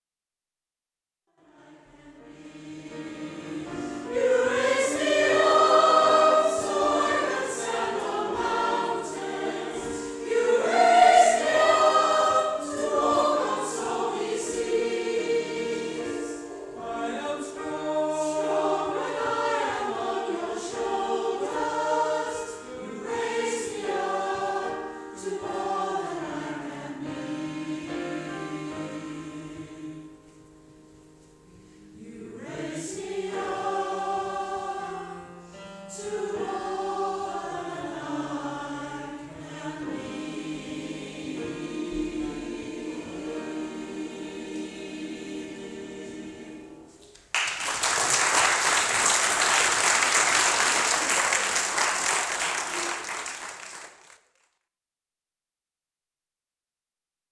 We are one of the largest mixed gender choirs in the area
A selection of audio excerpts from various performances by the Barry Community Choir, recorded at venues across South Wales.
You-Raise-Me-Up-LIVE-1.wav